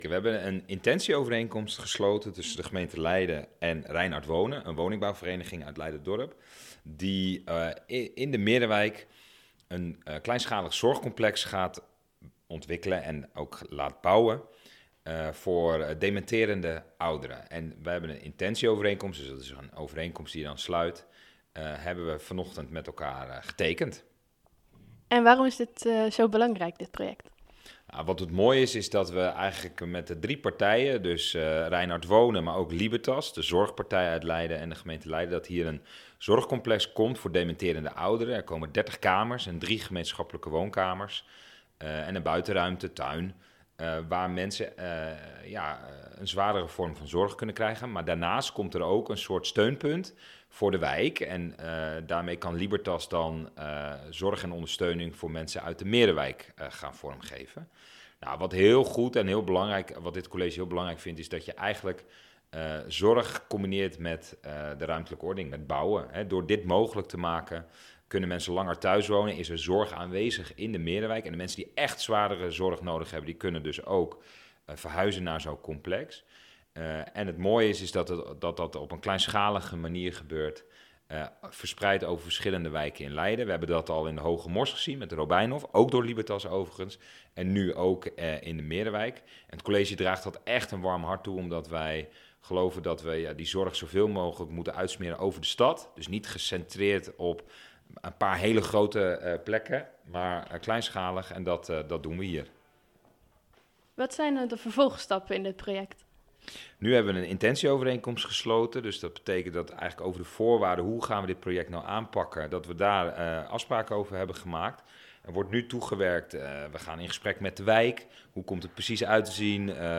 Wethouder Julius Terpstra vertelt over de IOK en project Broekhof.